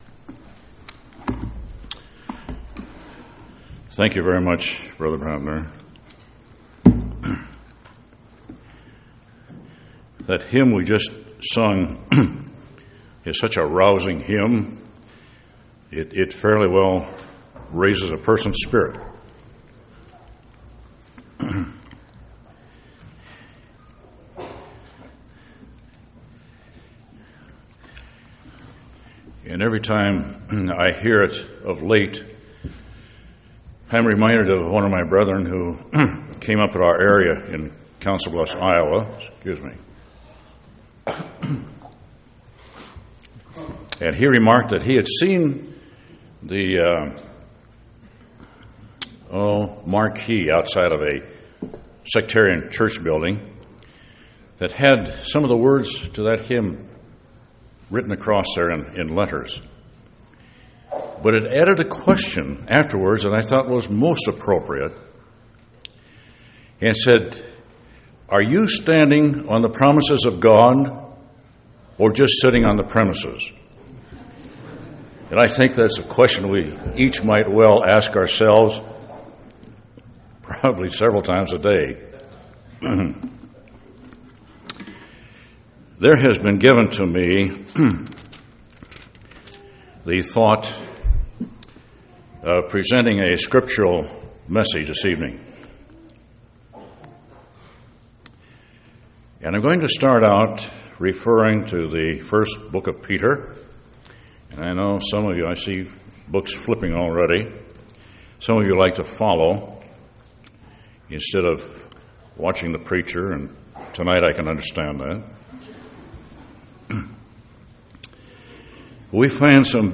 Event: General Church Conference